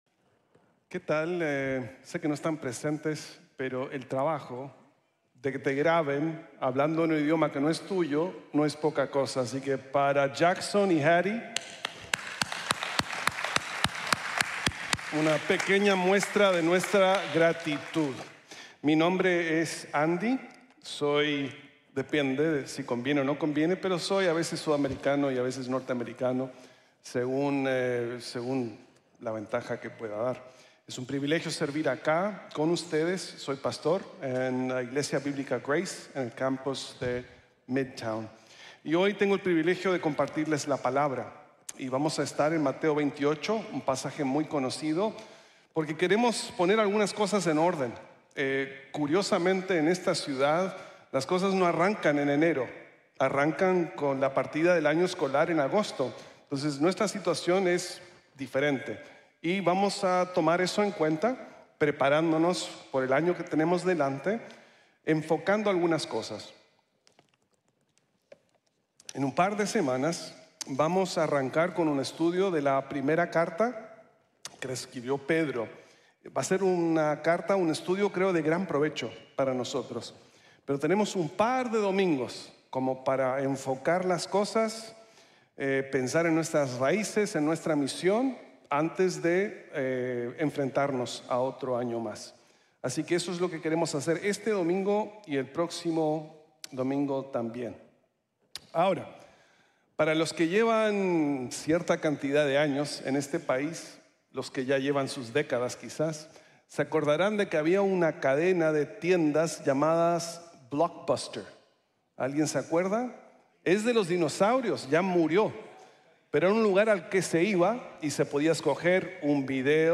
Los discípulos hacen discípulos | Sermón | Grace Bible Church